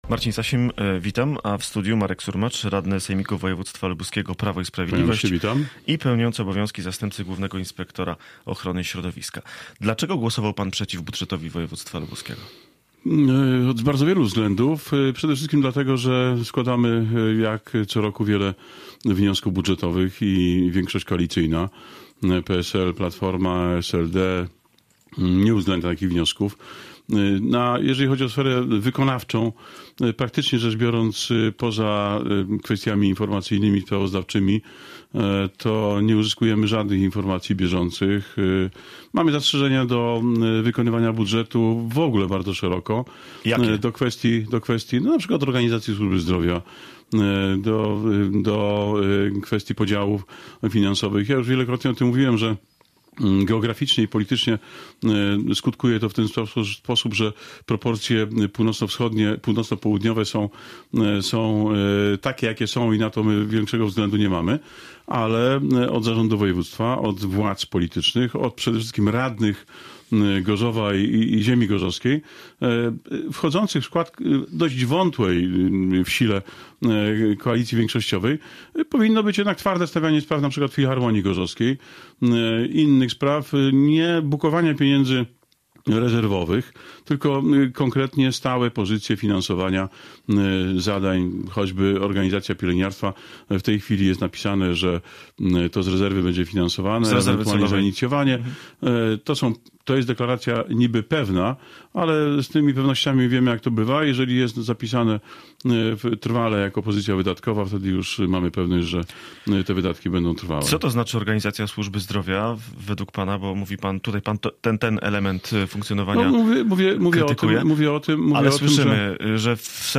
Z wojewódzkim radnym PiS rozmawiał